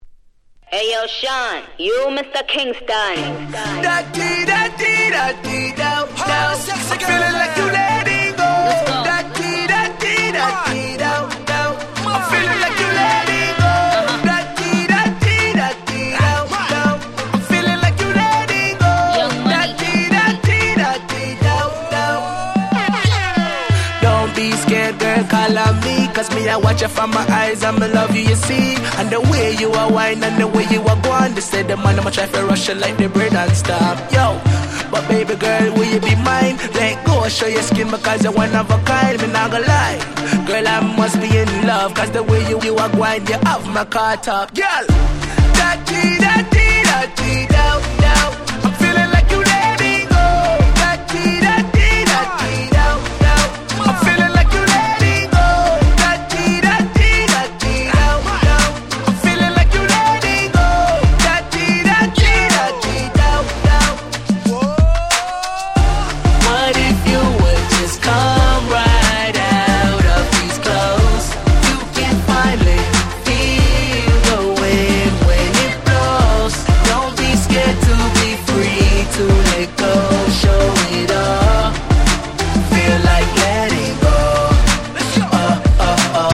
10' Smash Hit R&B !!